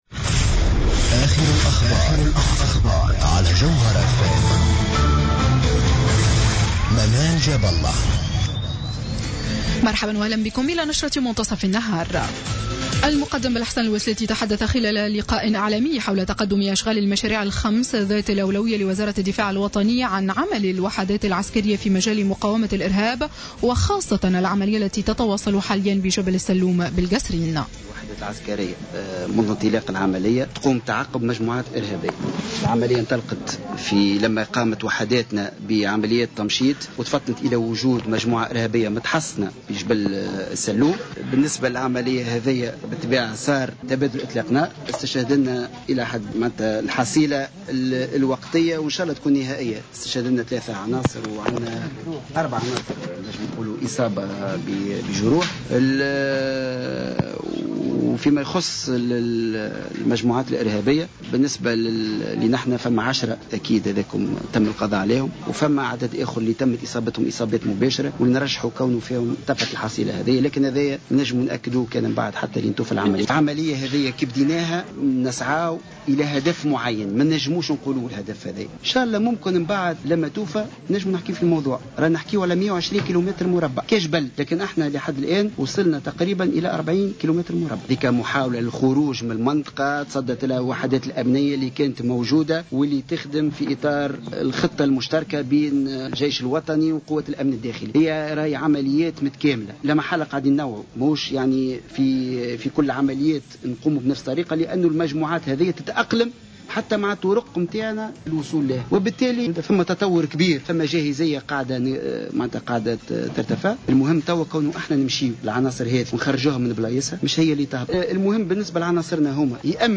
نشرة أخبار منتصف النهار ليوم السبت 09 ماي 2015